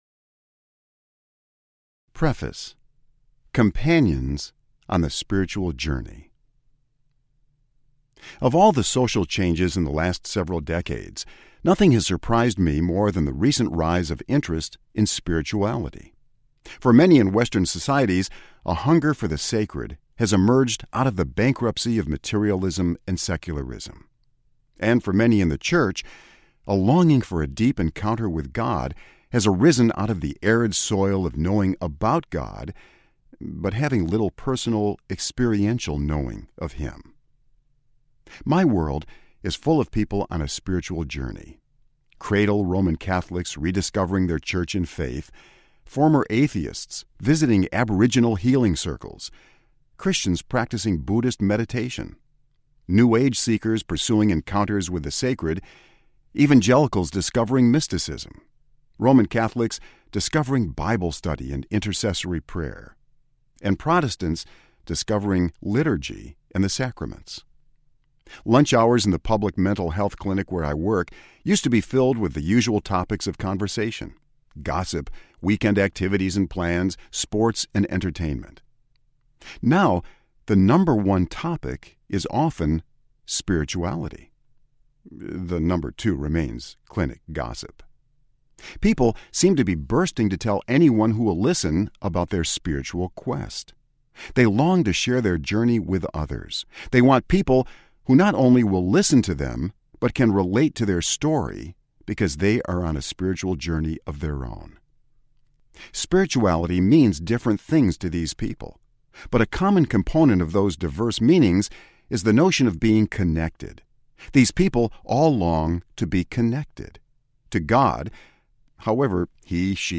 Sacred Companions Audiobook
7.5 Hrs. – Unabridged